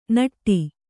♪ naṭṭi